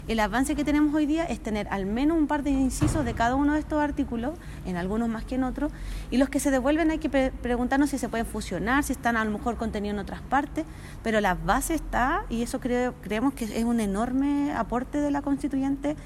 La co-coordinadora de la instancia, Loreto Vallejos, aseguró que esto significa un gran avance para el país y se refirió a las normas rechazadas.